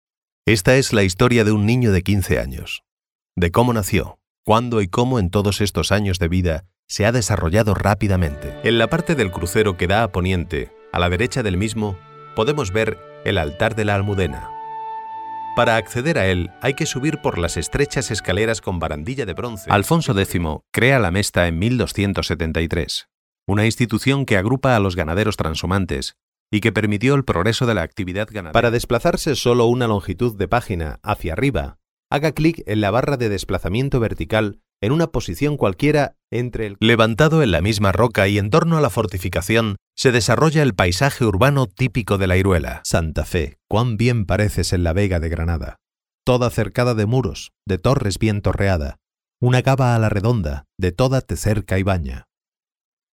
Adulto joven, adulto +40, español neutro, español andaluz, Publicidad, Documental, E-learning, Dibujos animados, Jingles, Canciones, Doblaje, Juegos, Presentaciones, Podcasts/internet, Sistema de teléfono, Moderación (on), Audiolibros, estudio propio.
Sprechprobe: eLearning (Muttersprache):
Type of voice: Spanish. Tenor, young adult, Adult +40.